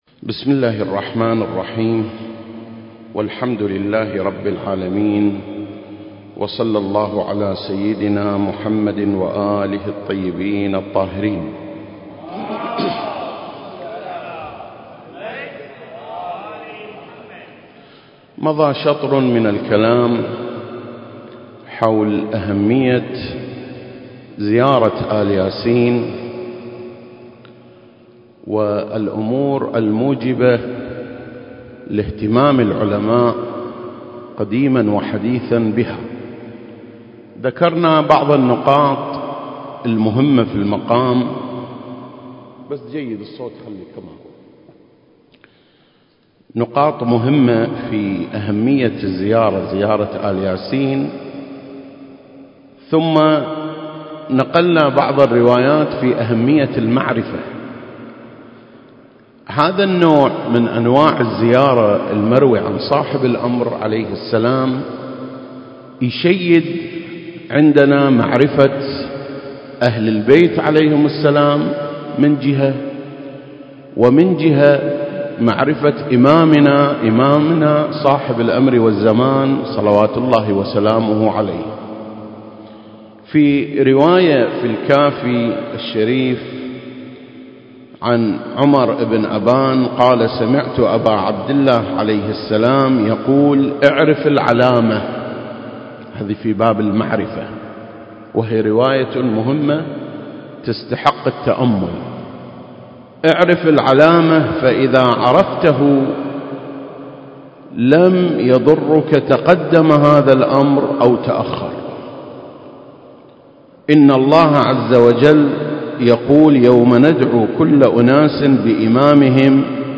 سلسلة: شرح زيارة آل ياسين (4) - الناحية المقدسة 1 المكان: مسجد مقامس - الكويت التاريخ: 2021